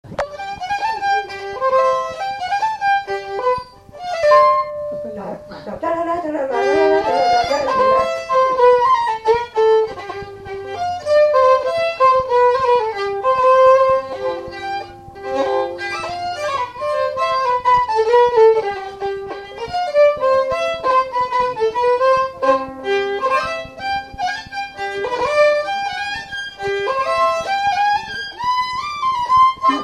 Instrumental
danse : quadrille
Pièce musicale inédite